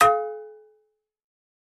fo_fryingpan_clang_02_hpx
Frying pans clang together. Clang, Frying Pan